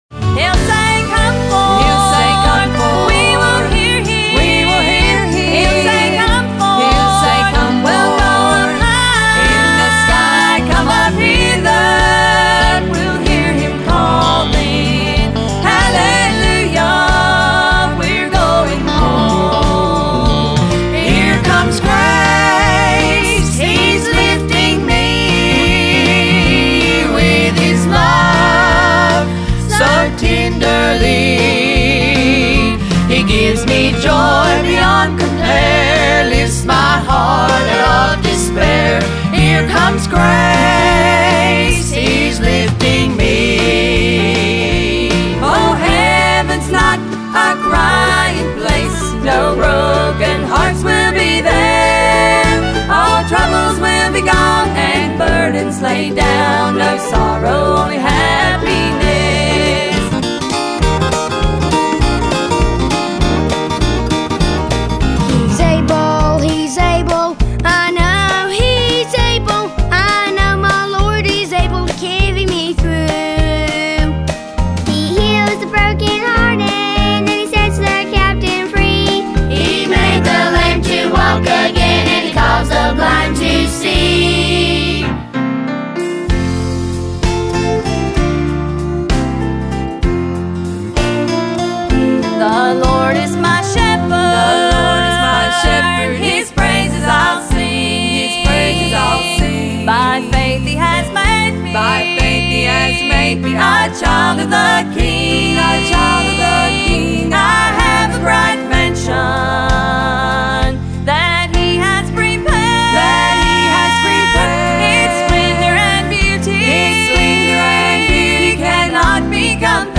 Celebrating 50 years of singing Southern Gospel Music!